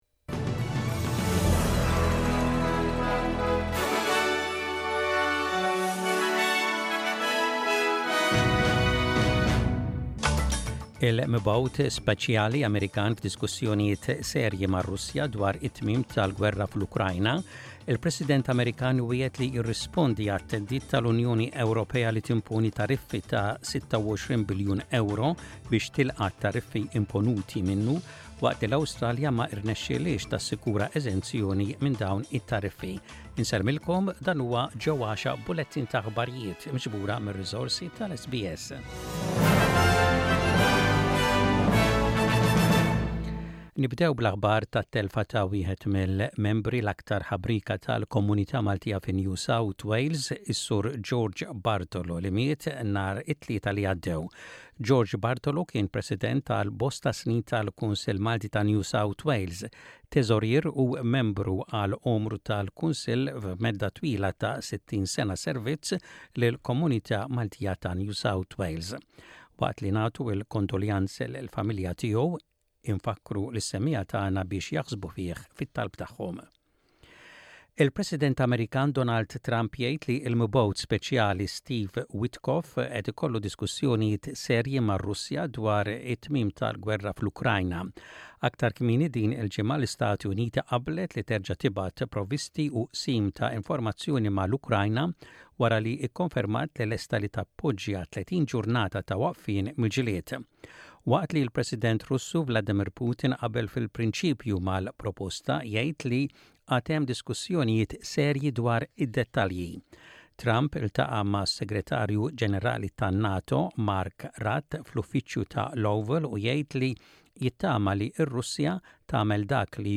Aħbarijiet bil-Malti: 14.03.25